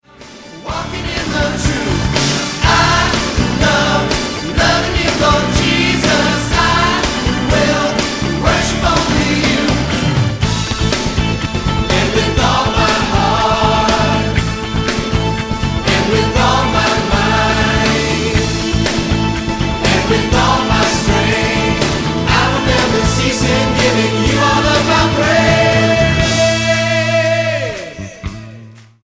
STYLE: MOR / Soft Pop
The musical arrangements are slick, but not too much so.